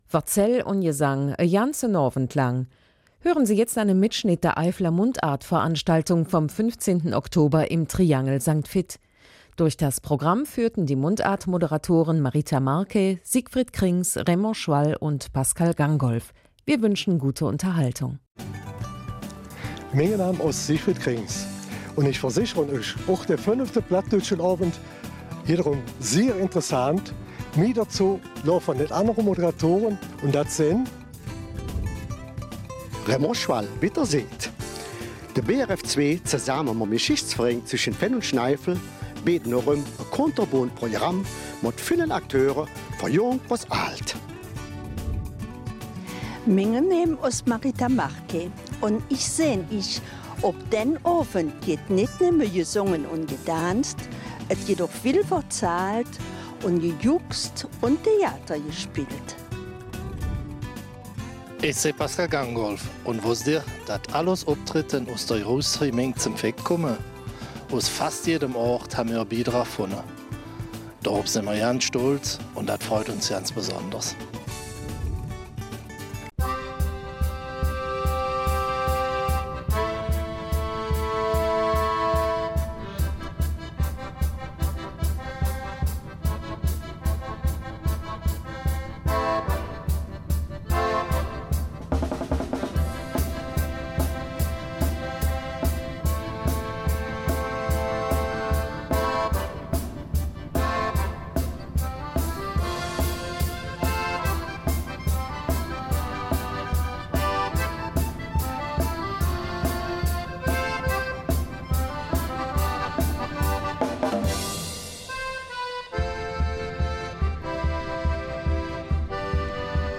Es wurde vier Stunden lang nur Platt gesprochen und gesungen am 15. Oktober im vollbesetzten Triangel St. Vith bei der fünften Auflage der Eifeler Mundartveranstaltung, zu der BRF und der Geschichts- und Museumsverein geladen hatten. Es waren fast ausschließlich Mitwirkende aus der Großgemeinde St. Vith vertreten, quer durch alle Altersgruppen.
Für einige der kleinen Sängerinnen und Sänger war es eine Bühnenpremiere!